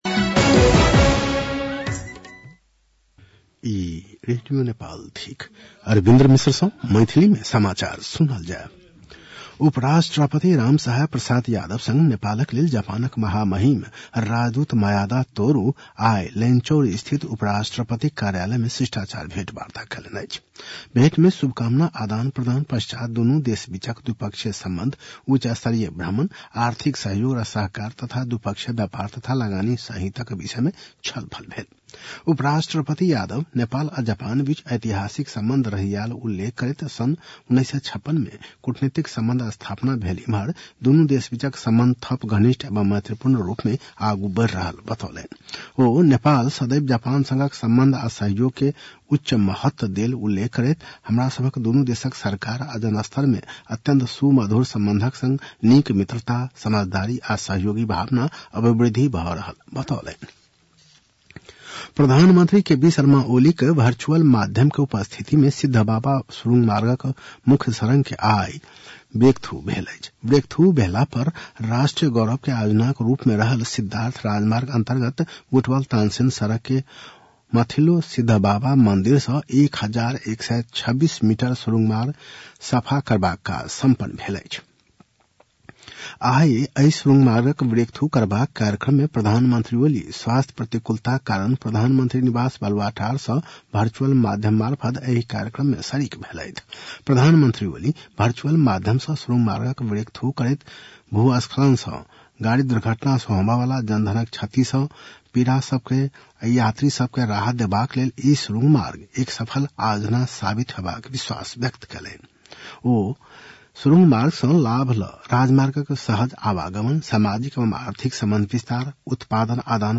मैथिली भाषामा समाचार : १२ माघ , २०८१
Maithali-news-10-11.mp3